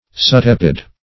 Subtepid \Sub*tep"id\